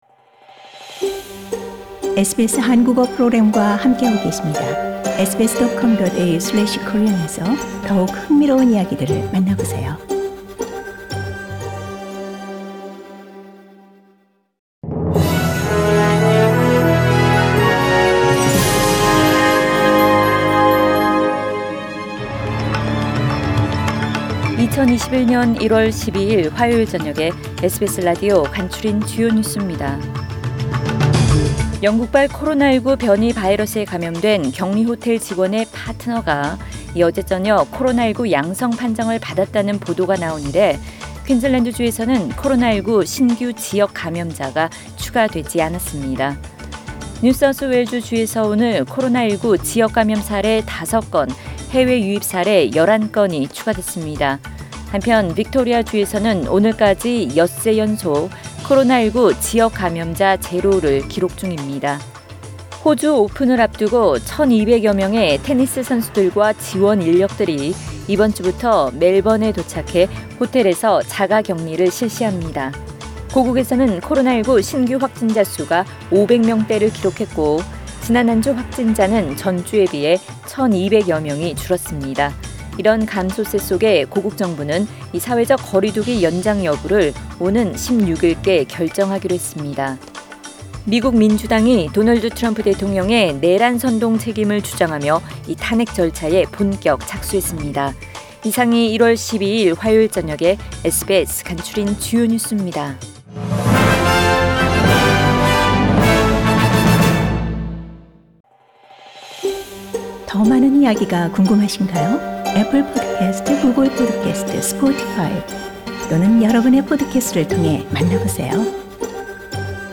SBS News Outlines…2021년 1월 12일 저녁 주요 뉴스